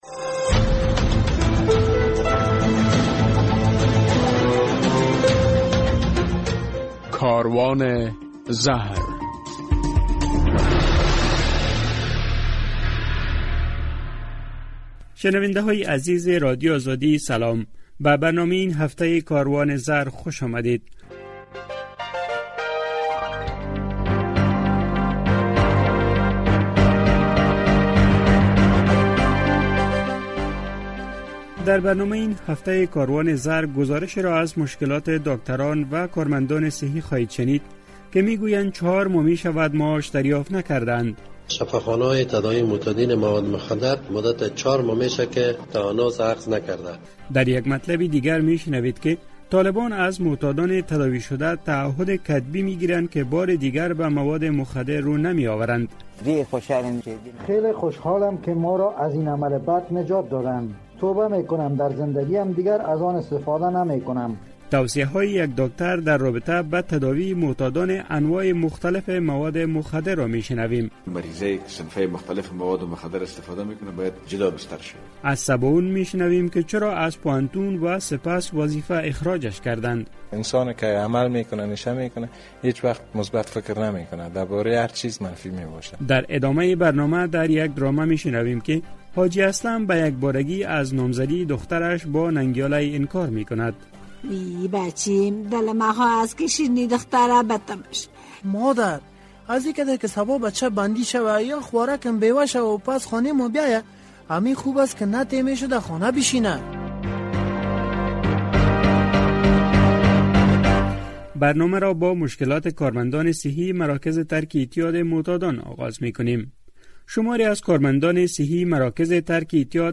در برنامه این هفته کاروان زهر این مطالب را می‌توانید بشنوید: چهار ماه می‌شود که کارمندان مراکز ترک اعتیاد تنخواه نگرفته اند طالبان از معتادان تداوی شده تعهد کتبی می گیرند که پس از تداوی بار دیگر به مواد مخدر رو نمی آورند مصاحبه در مورد تداوی افرادیکه که به انواع مختلف مواد مخدر معتاد اند اما...